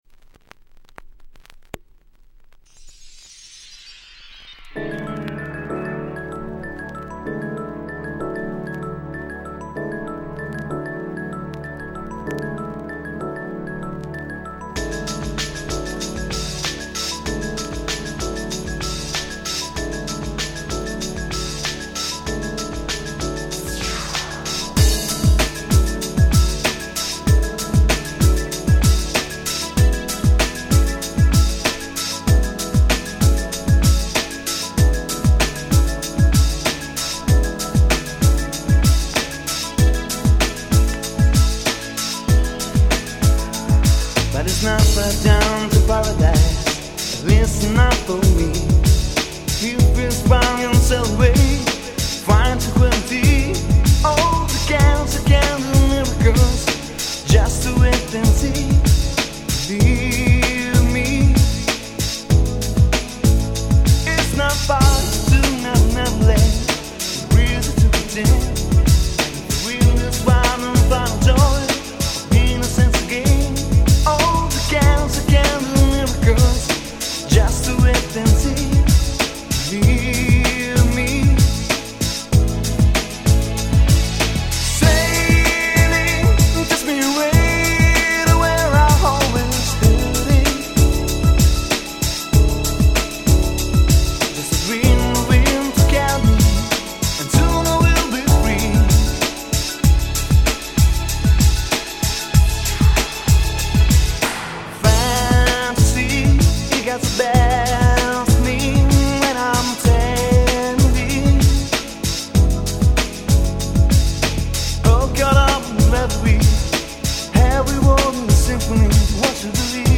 94' Super Nice Cover Ground Beat !!
Italy産Ground Beat最高峰！！